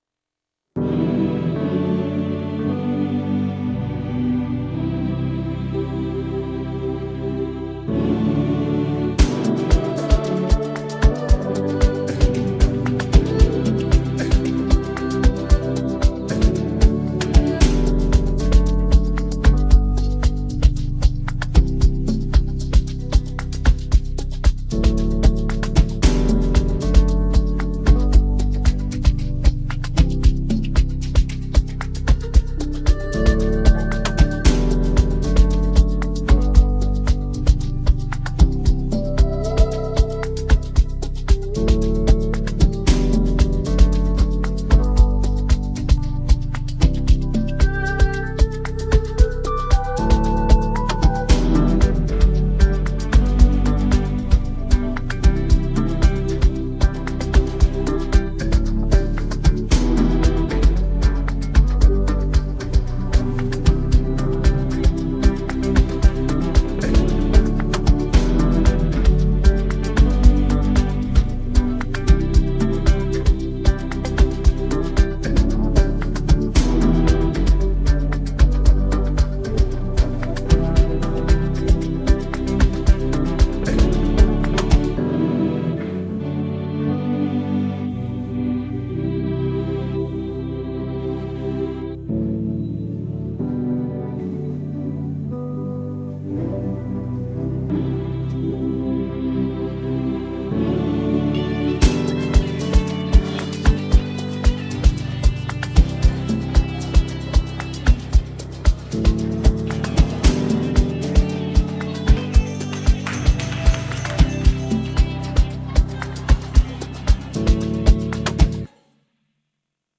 Afro popAfrobeatsAmapaino